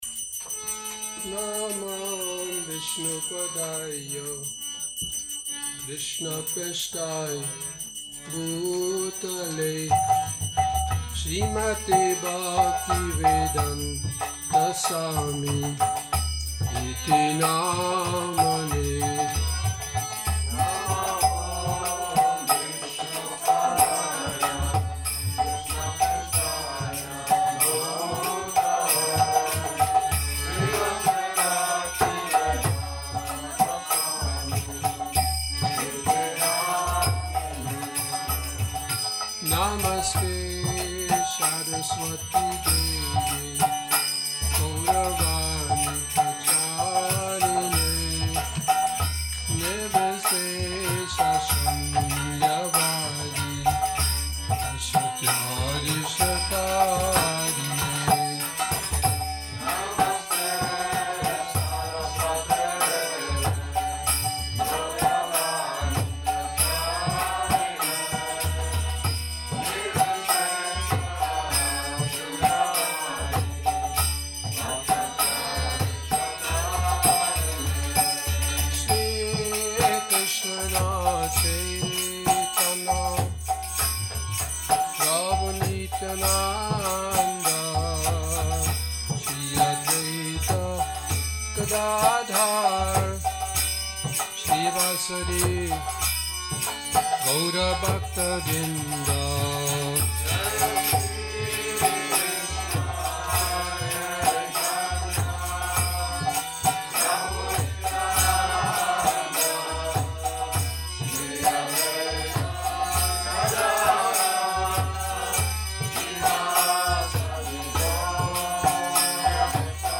Kírtan Nedělní program